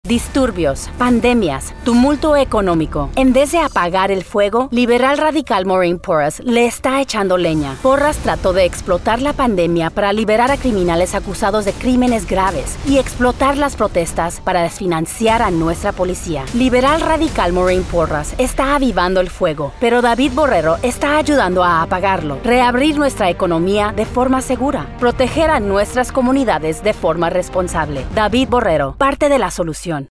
Female Spanish Republican Political Voiceover
Spanish attack ad